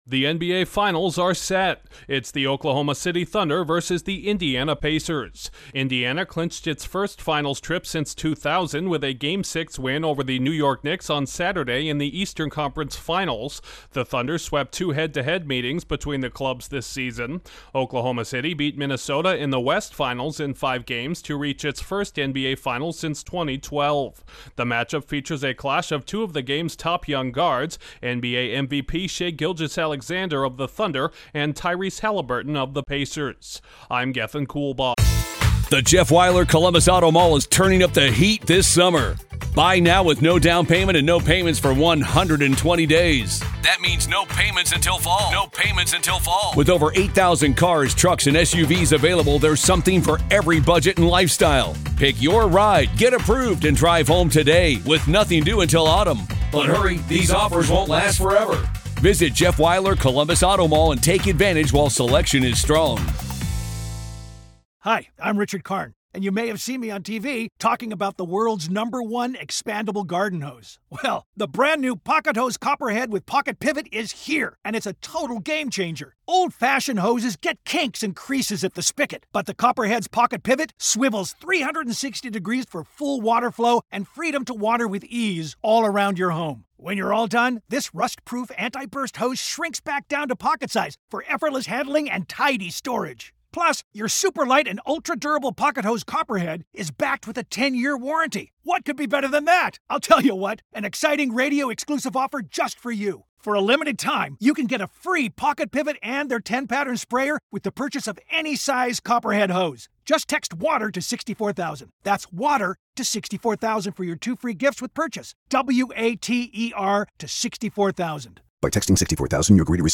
A battle of two All-NBA guards will headline this year’s NBA Finals beginning Thursday in Oklahoma City. Correspondent